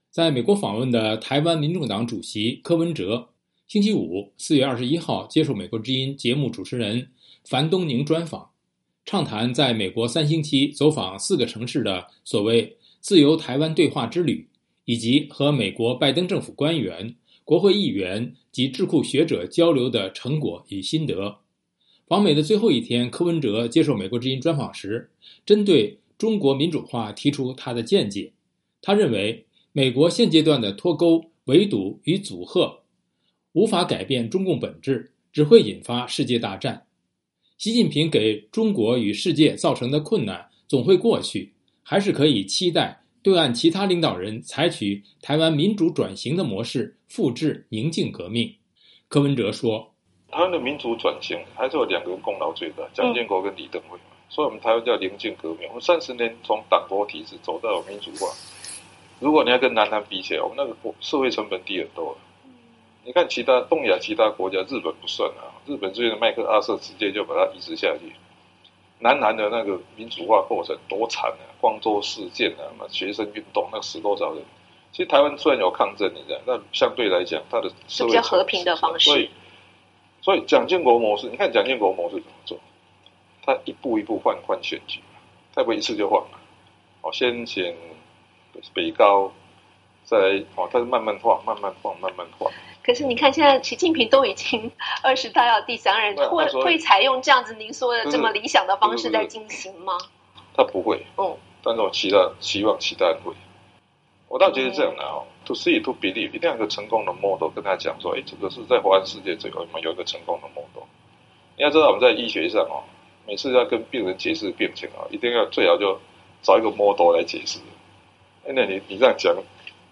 访美的最后一天，柯文哲接受美国之音专访时针对中国民主化提出他的见解，他认为美国现阶段的脱钩、围堵与吓阻无法改变中共本质，只会引发世界大战。习近平给中国与世界造成的困难总会过去，还是可以期待对岸其他领导人采用台湾民主转型的模式复制宁静革命。